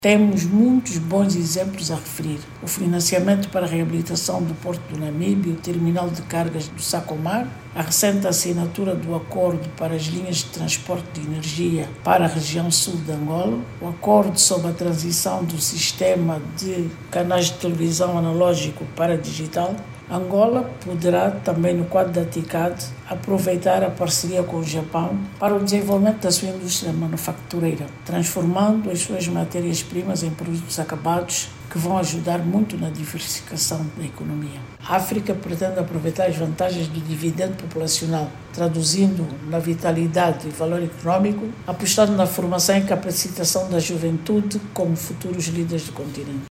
Para a embaixadora de Angola no Japão, Teodolinda da Cruz a conferencia internacional de Tóquio para o desenvolvimento em Africana é uma excelente oportunidade para Angola atrair investimentos em setores estratégicos da economia nacional.